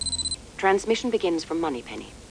1 channel
007 newmail.mp3